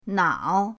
nao3.mp3